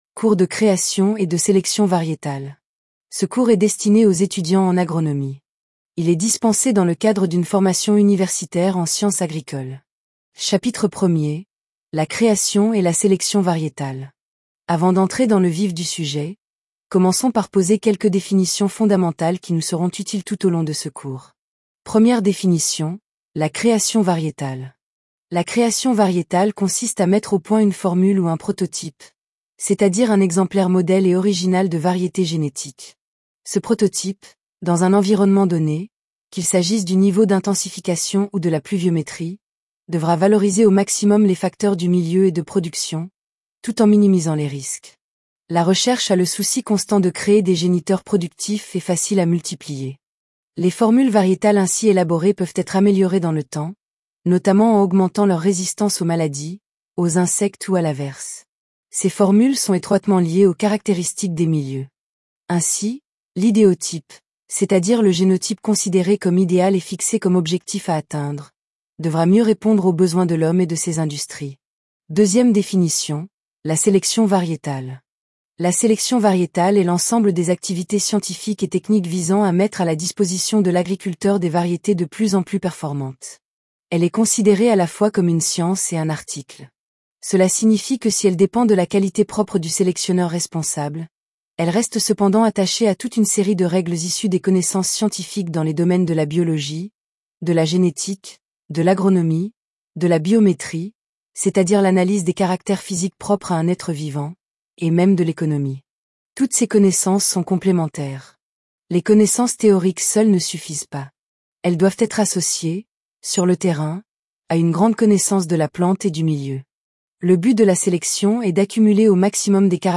Podcast : Cours Selection Varietale Tts
cours_selection_varietale_TTS.mp3